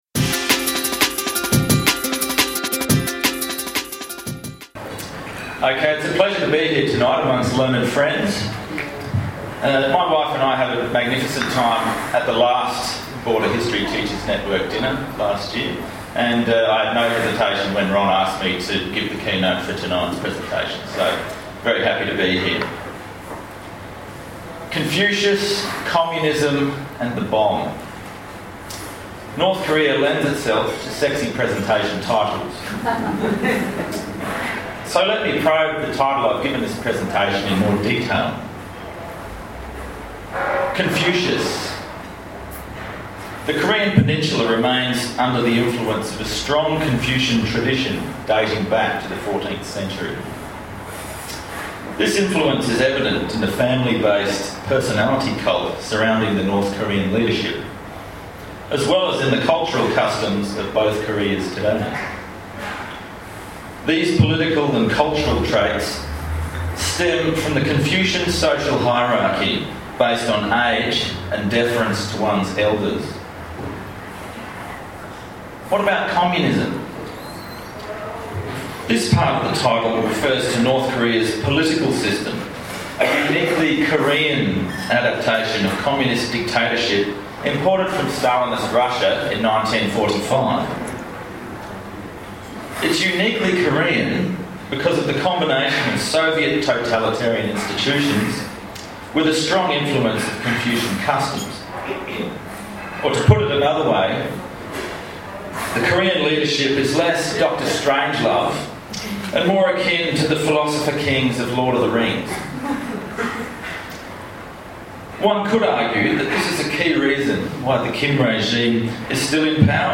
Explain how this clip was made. Border History Teachers’ Network — Dinner Forum The Crown Lounge, New Albury Hotel.